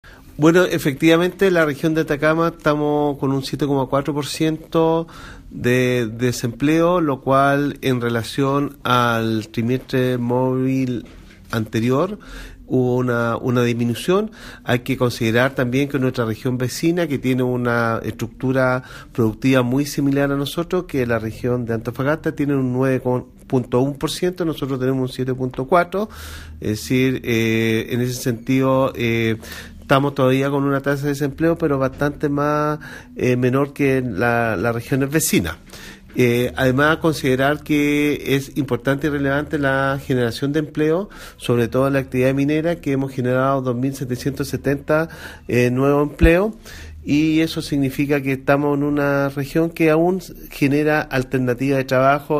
Al respecto el Seremi de Economía, Mario Silva, comentó que “efectivamente tenemos un 7,4% de desempleo en Atacama lo cual en relación al trimestre móvil anterior hubo una disminución lo que nos deja en una mejor posición  considerando el panorama con nuestra región vecina como Antofagasta que tiene una estructura muy similar a la nuestra y respecto a la macro zona norte”.
Seremi-economía-1.mp3